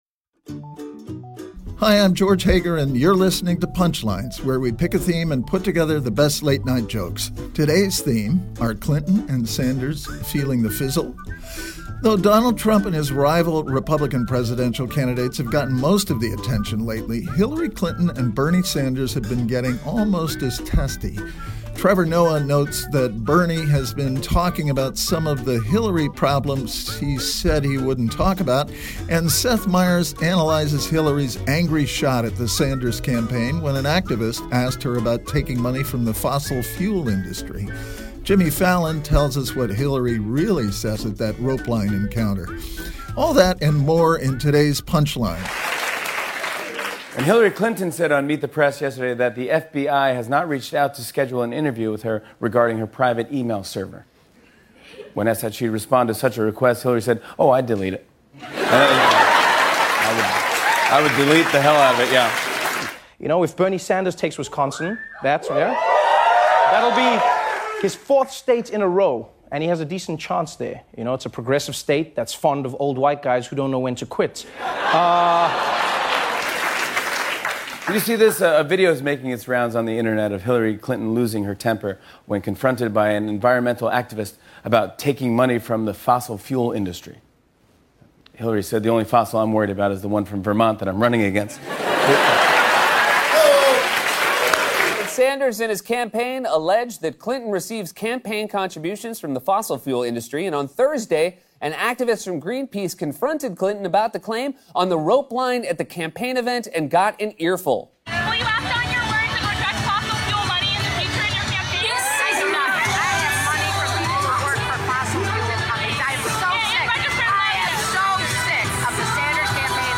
The late-night comics track the downfall of the Clinton-Sanders love fest.